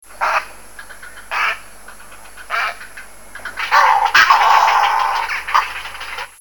Fichier:Heron chant.mp3 — Wiki-Anjou
Heron_chant.mp3